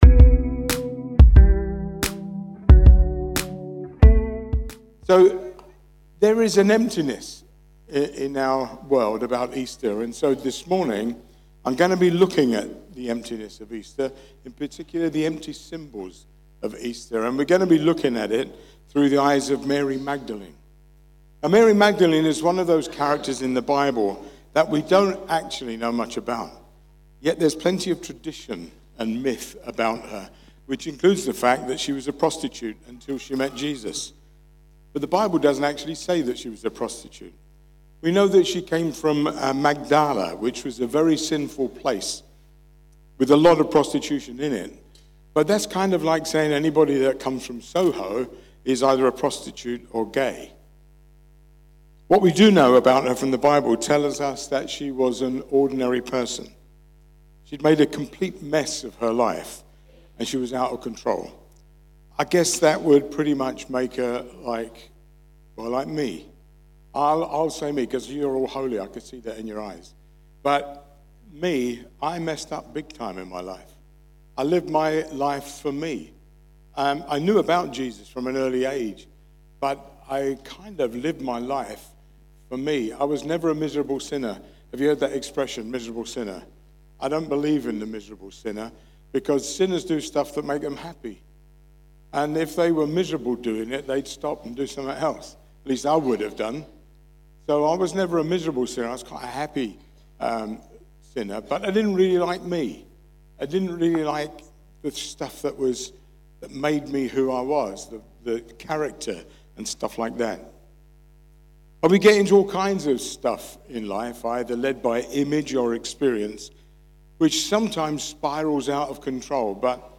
Rediscover Church Newton Abbot | Sunday Messages | Easter Sunday, 5th April 2026